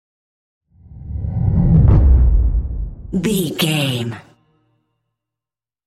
Cinematic whoosh to hit deep fast
Sound Effects
Atonal
Fast
dark
intense
tension
woosh to hit